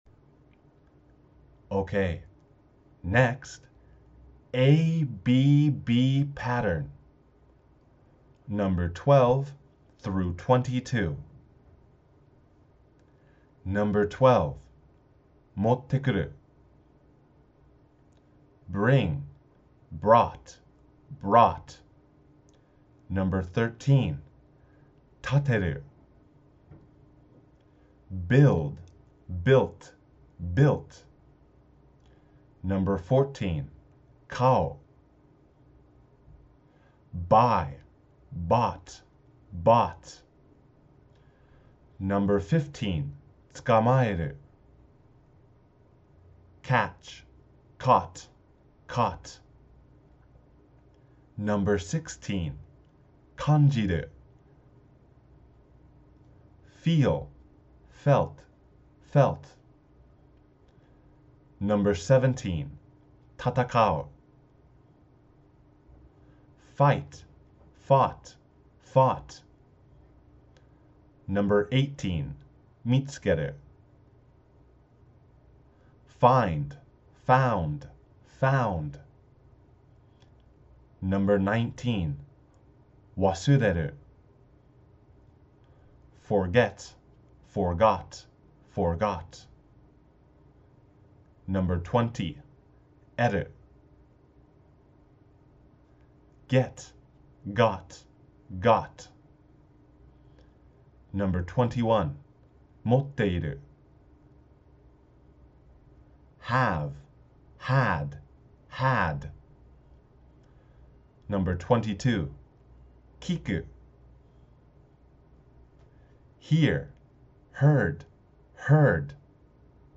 今日はNo.1〜No.3まで（不規則変化表では１〜２２）です。 →英語音源No1 →英語音源No.2 →英語音源No.3